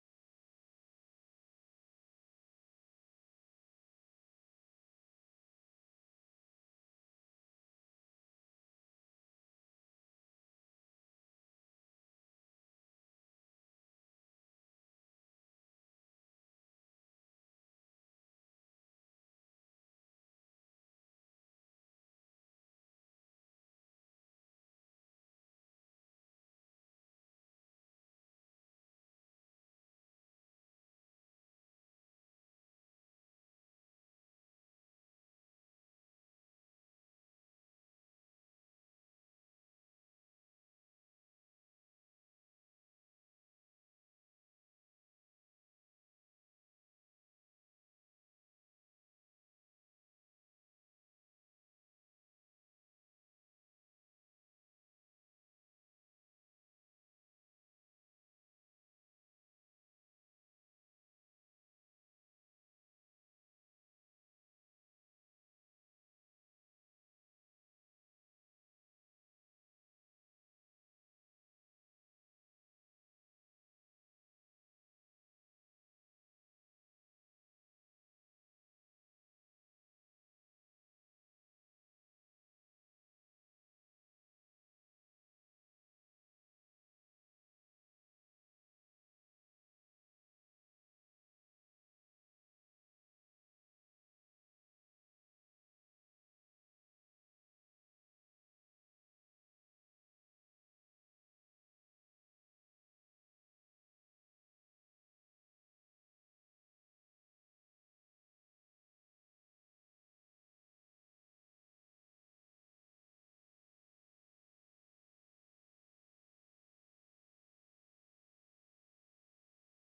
interview Boston Unversity students on how they plan to vote in the 2024 election